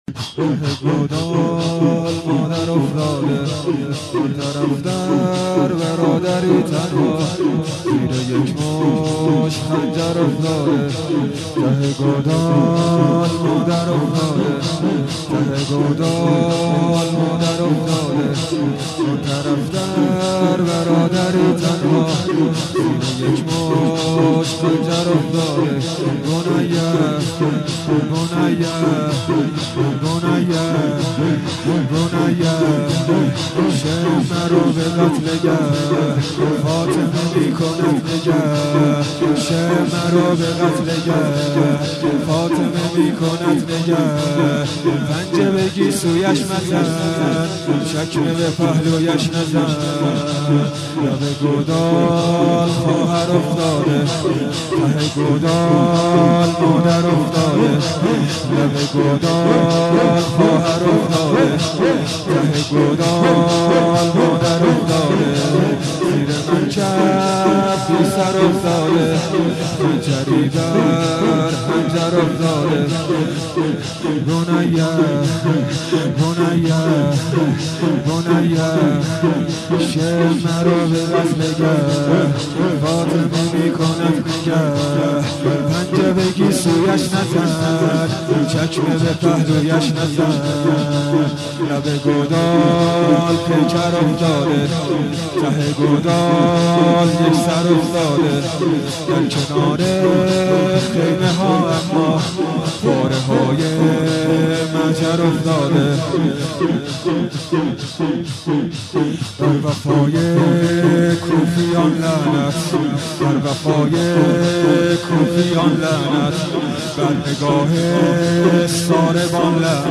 شور